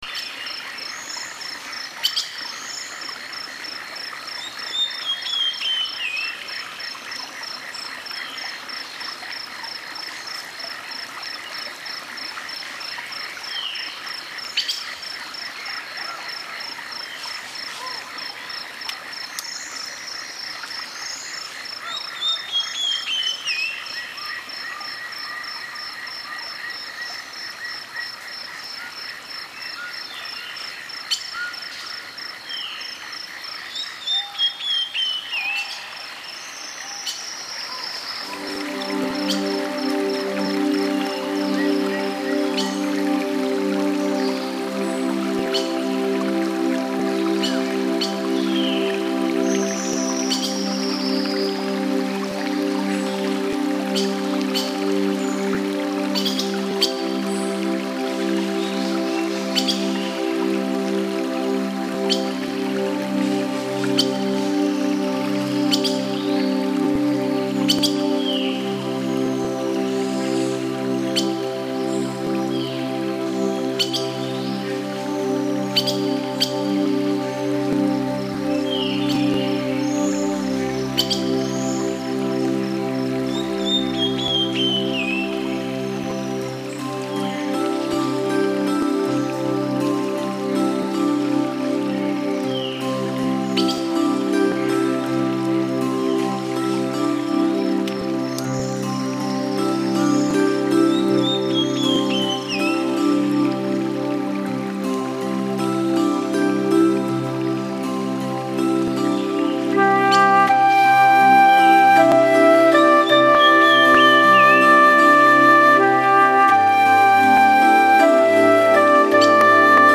[Relaxmuzak]